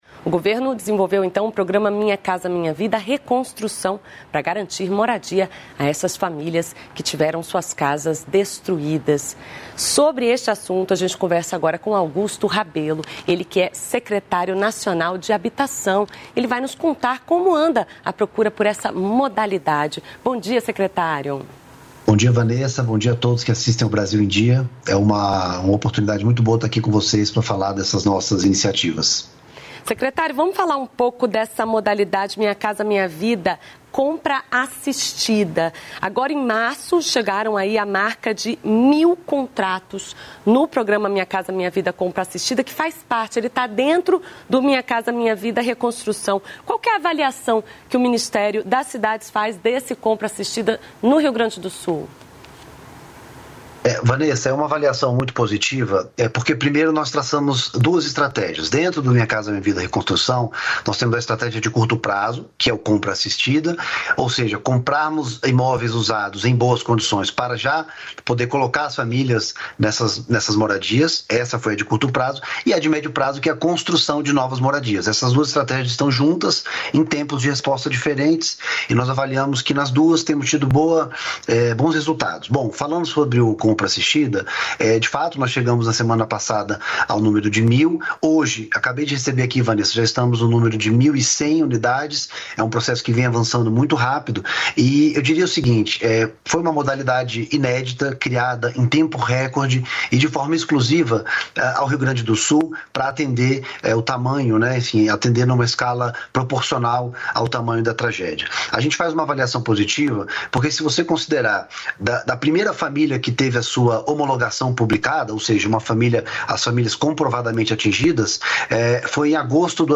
Brasil em Dia - Entrevista